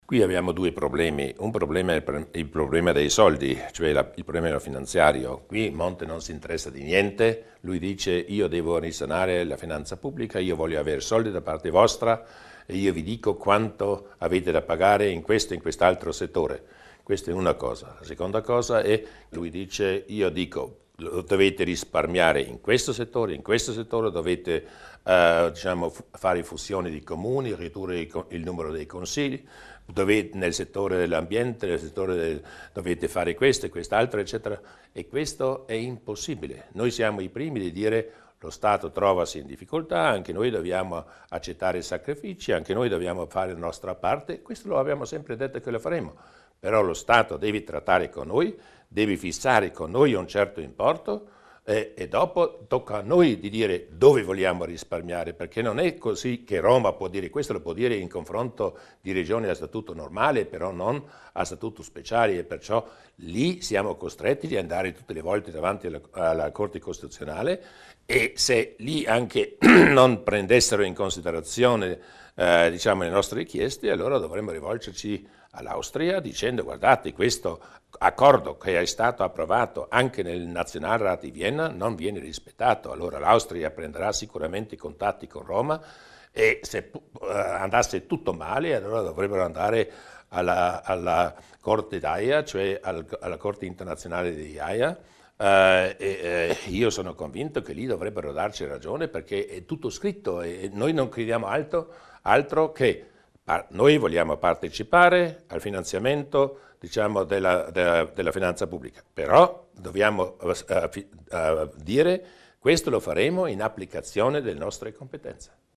Così, durante l'incontro estivo con i media a Falzes, il presidente della Giunta provinciale Luis Durnwalder ha commentato i difficili rapporti tra Roma e Bolzano.